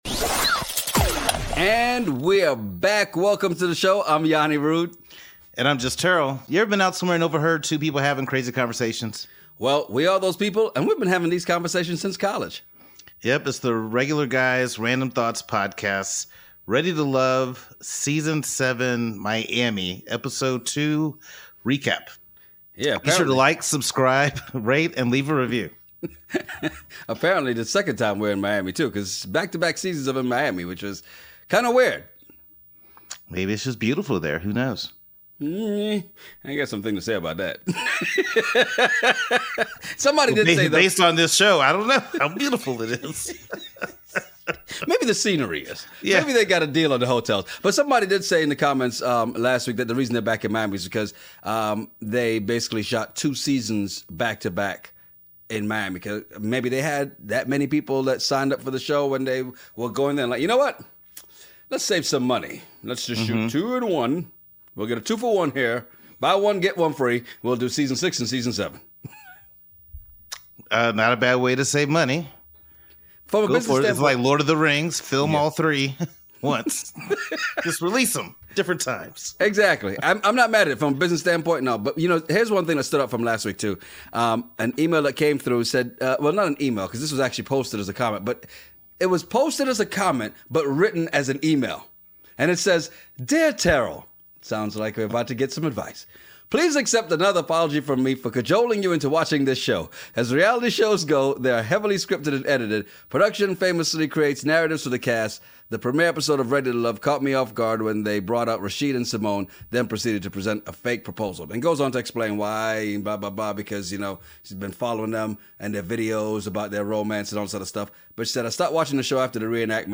Headliner Embed Embed code See more options Share Facebook X Subscribe 4 new singles were brought into the mix Ever been somewhere and overheard two guys having a crazy conversation over random topics?